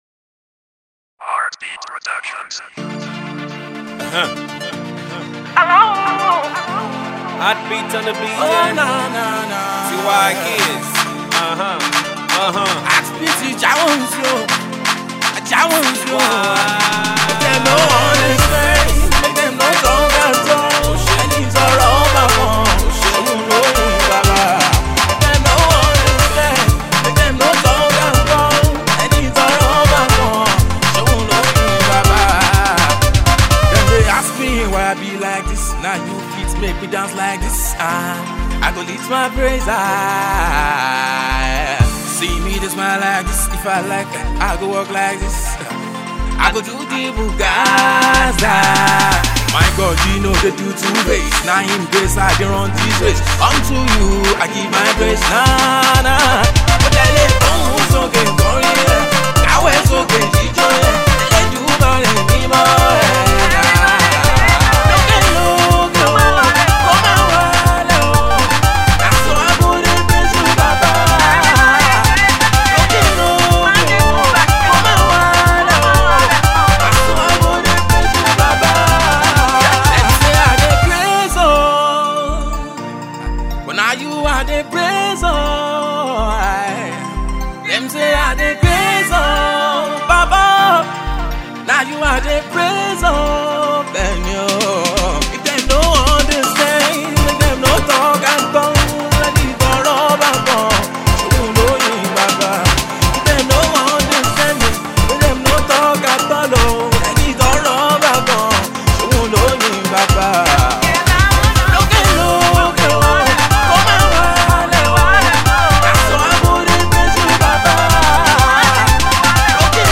uptempo funky hiphop praise song